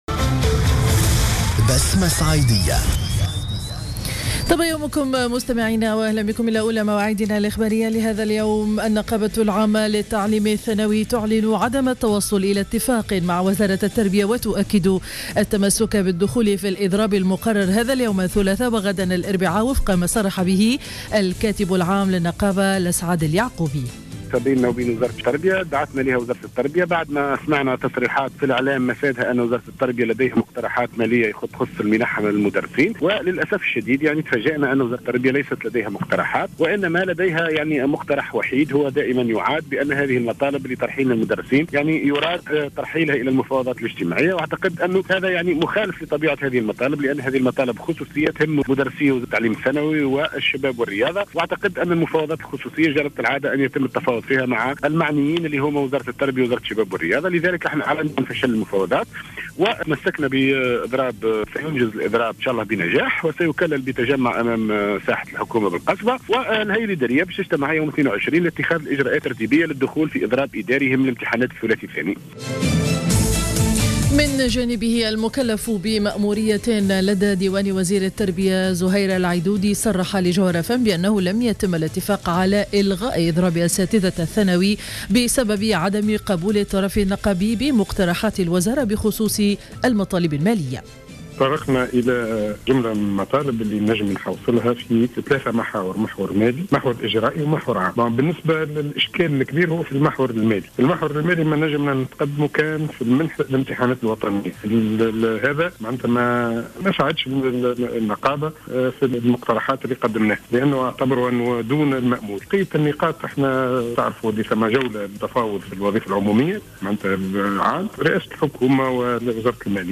نشرة اخبار السابعة صباحا ليوم الثلاثاء 17 فيفري 2015